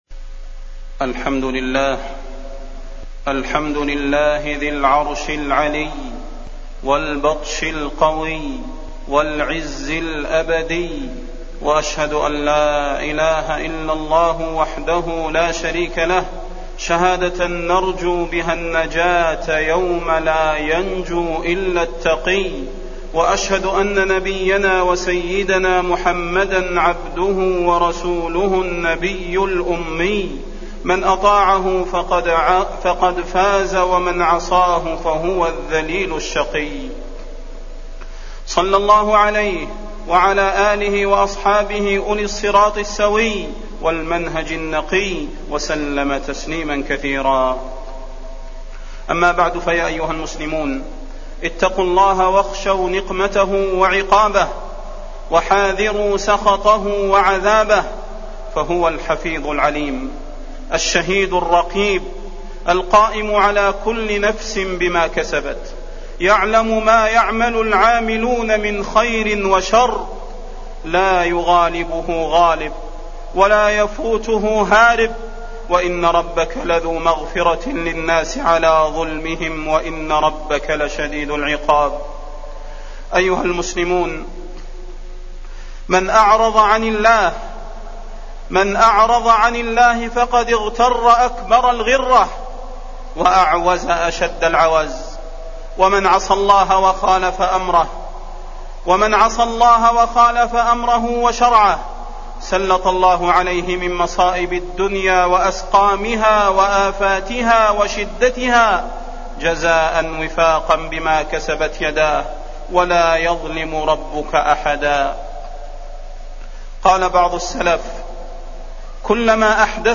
فضيلة الشيخ د. صلاح بن محمد البدير
تاريخ النشر ٢٤ شوال ١٤٢٩ هـ المكان: المسجد النبوي الشيخ: فضيلة الشيخ د. صلاح بن محمد البدير فضيلة الشيخ د. صلاح بن محمد البدير زوال النعمة بالذنوب